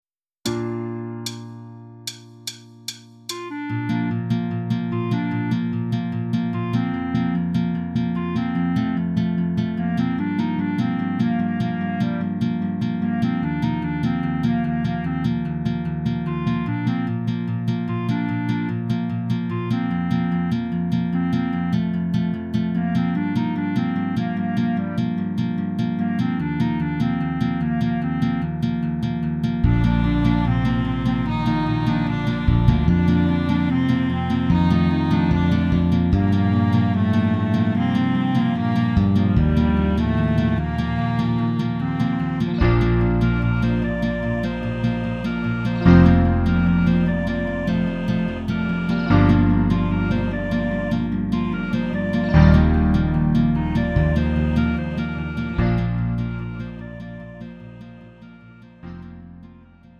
음정 -1키 3:28
장르 구분 Lite MR